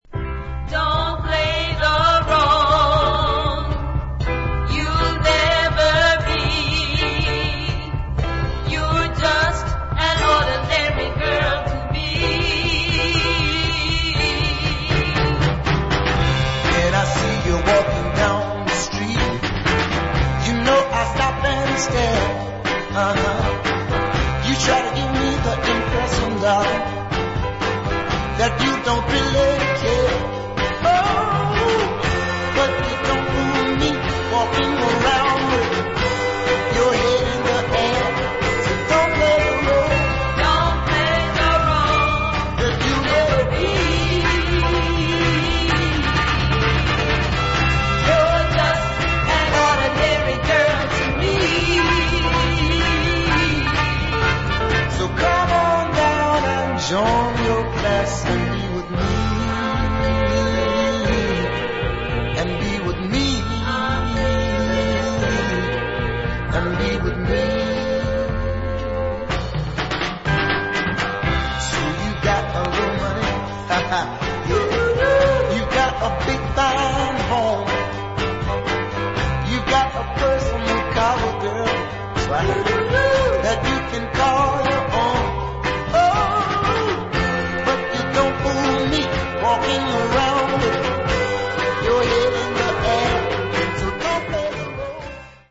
brilliant 60's Northern Soul dancer